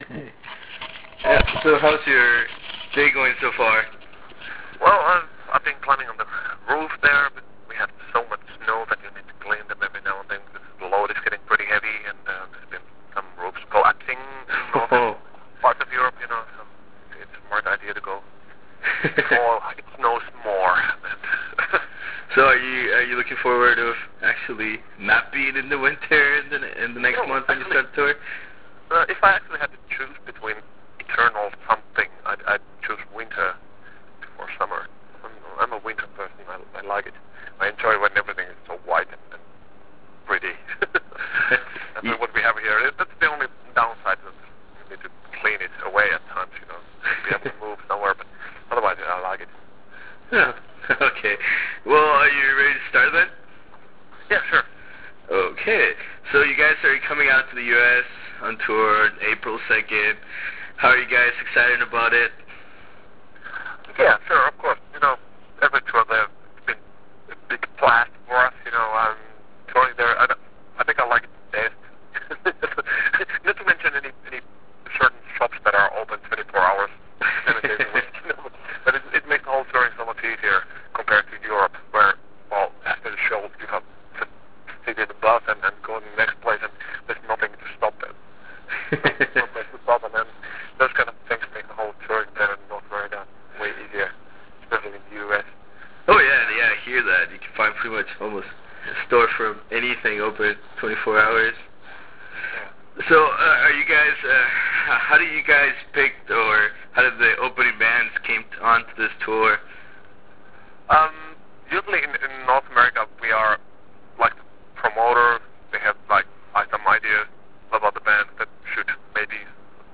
Interview with Tony Kakko - Sonata Arctica (Tour 2010)
In the eve of their upcoming North American tour, we managed to get a 20+ minutes interview with Sonata Arctica’s front man Tony Kakko. We talked about what to expect in terms of their tour in April, what is the band looking forward the most during this tour, how do they see their last album 6 months after it’s release, and their confirmed appearance at the first ever 70,000 tons of Metal cruise/festival next January.
Interview with Tony Kakko - Sonata Arctica (2010 Tour).wav